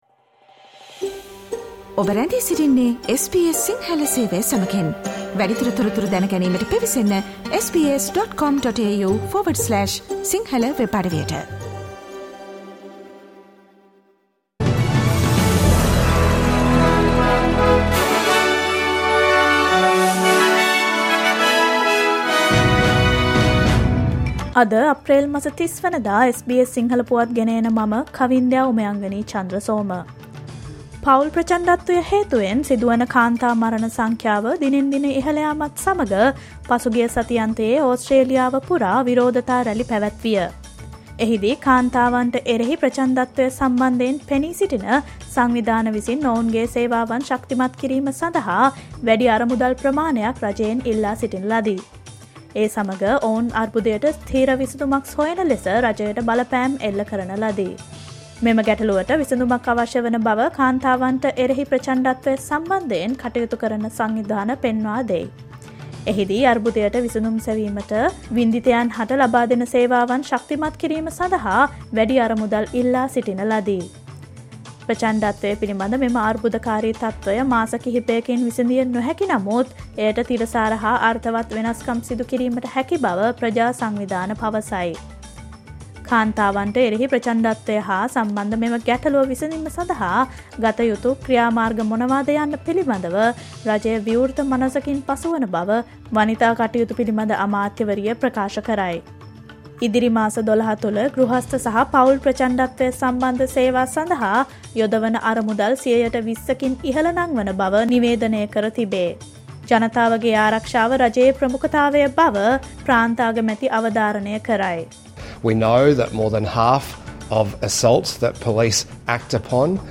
Australia's news in English, foreign and sports news in brief.